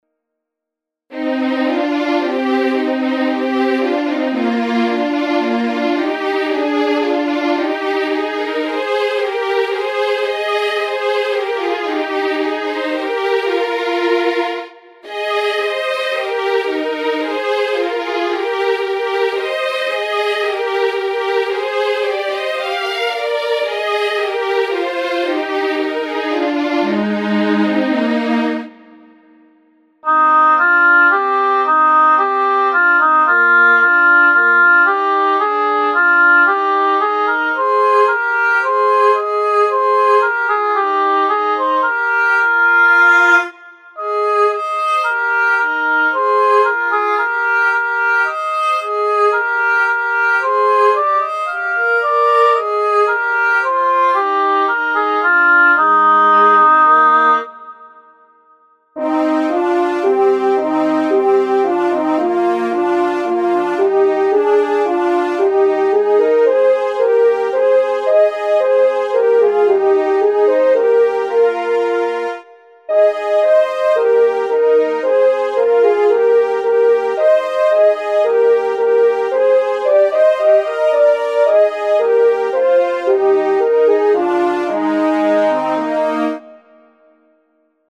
Sopran + Alt, 2-stimmig Es-Dur